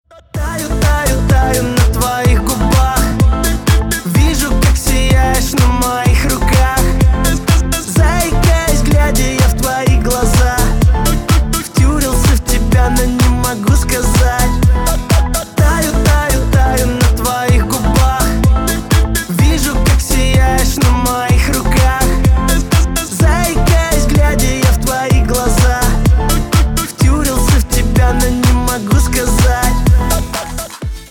• Качество: Хорошее
• Песня: Рингтон, нарезка
• Категория: Русские рингтоны